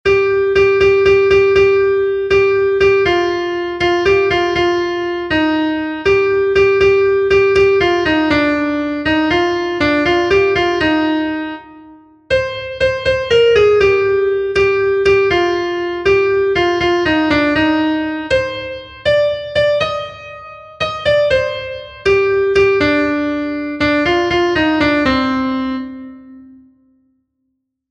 Sehaskakoa
Zortziko txikia (hg) / Lau puntuko txikia (ip)
A-B-C-D